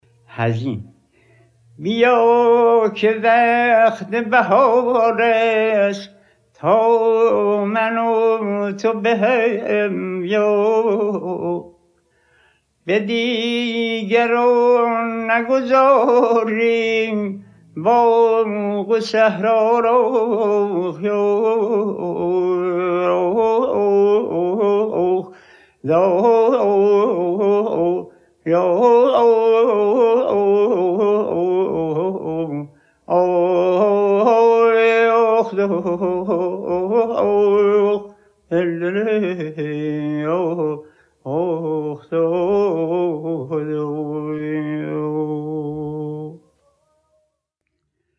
4- حزین - آواز بیات کرد